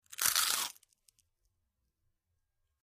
BiteManyPotatoChip PE390703
Bite Into Many Potato Chips, X7